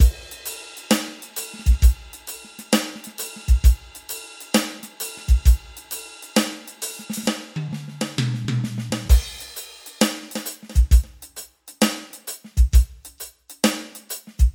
同样的节奏，但有一个骑钹和一个汤姆鼓的滚动。
标签： 132 bpm Pop Loops Drum Loops 2.45 MB wav Key : Unknown